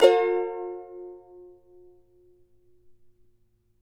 CHAR G MJ  U.wav